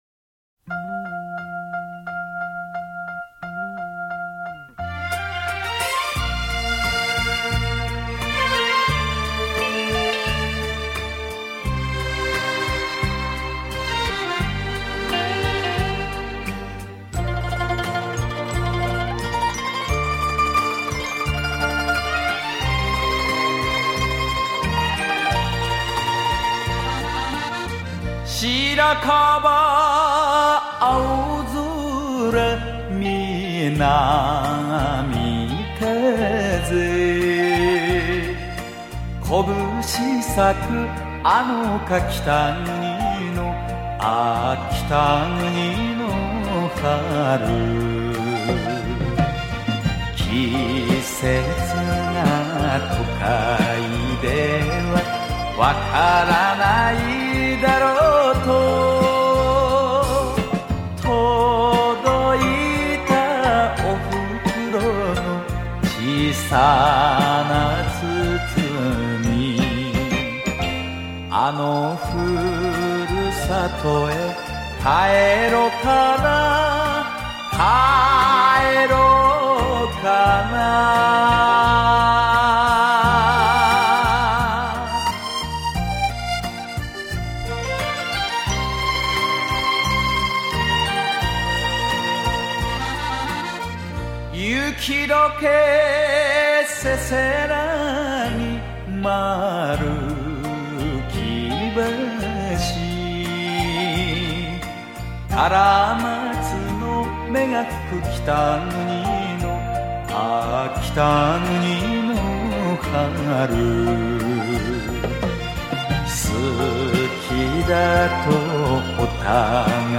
2人に共通している独特の訛と柔らかいトーンが聴く人を安心させるんだなぁ。